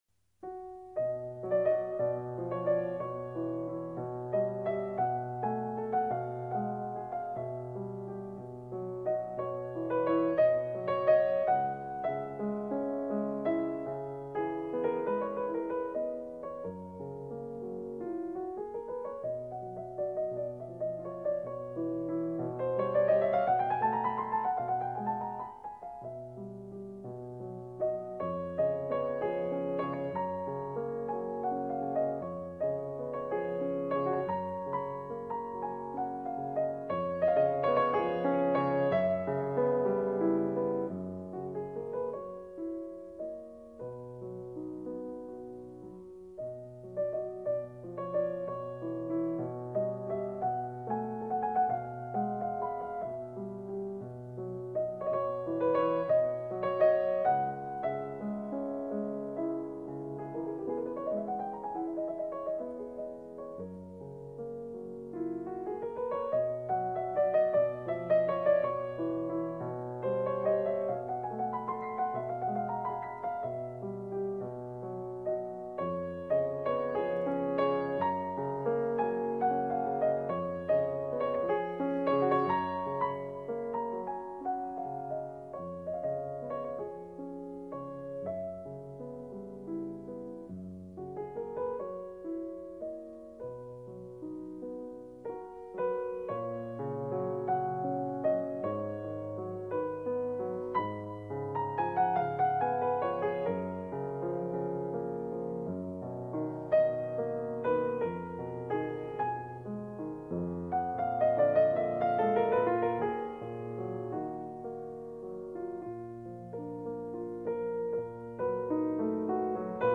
稍快板从优雅开头，中段转为激烈的戏剧化情绪，最后是优美的尾声。